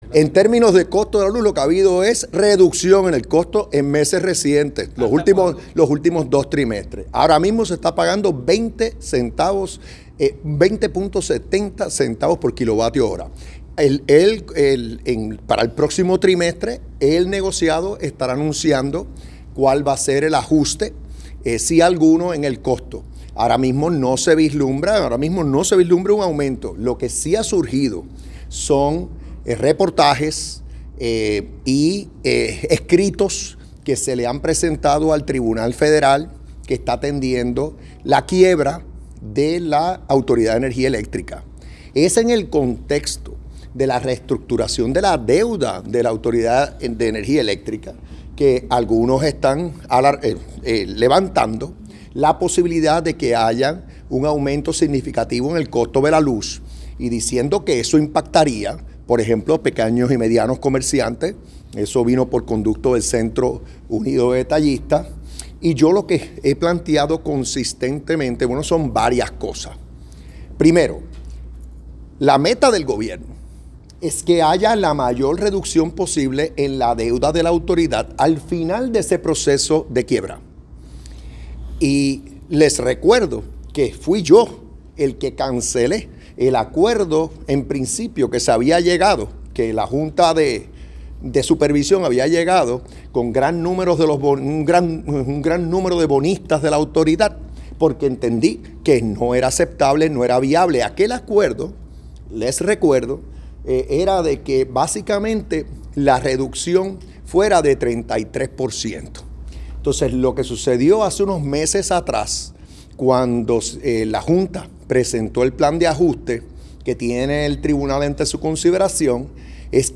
Y cuando llegue el final de este proceso de ajuste de deuda, el plan que surja, que cuando el tribunal lo confirme, de acuerdo con PROMESA, tiene que cumplir con las leyes y las reglamentaciones locales”, expresó el gobernador a preguntas de prensa.